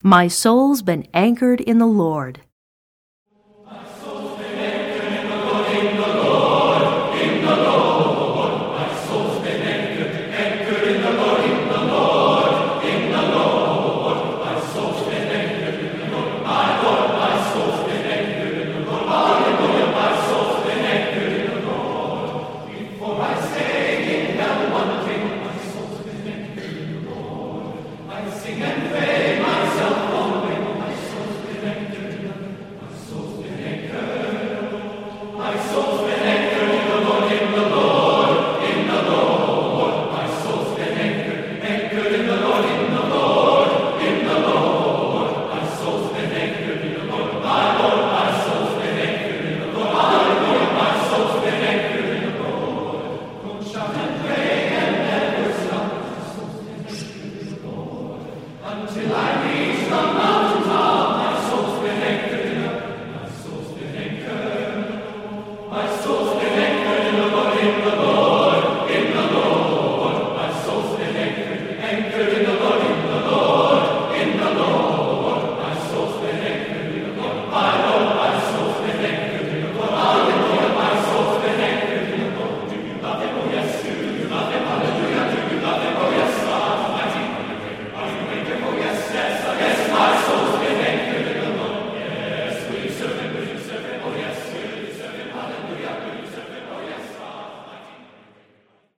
Composer: Spiritual
Voicing: TTBB